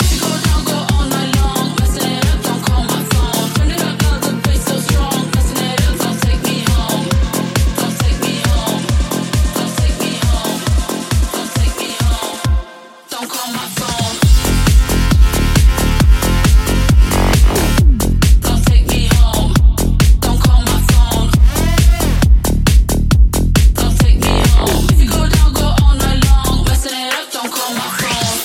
tech house hits
Genere: house,tec house,tecno,remix,hit